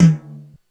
HiTom.wav